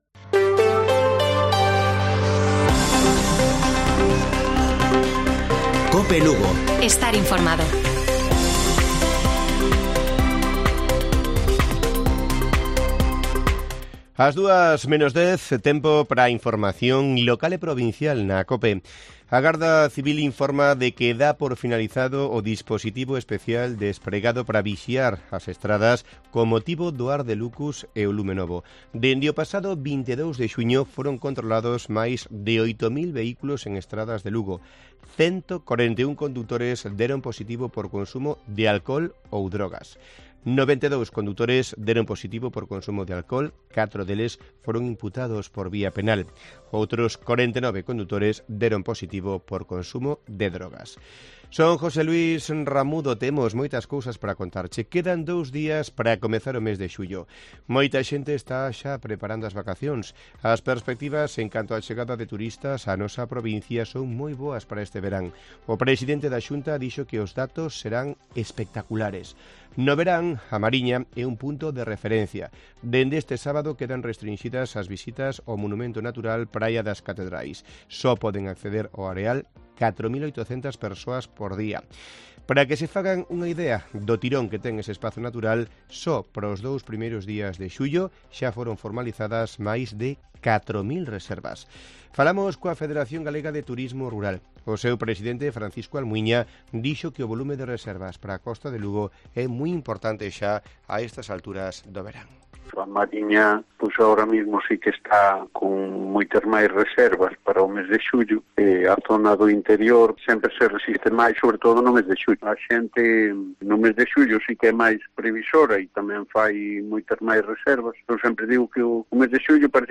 Informativo Mediodía de Cope Lugo. 28 de junio. 13:50 horas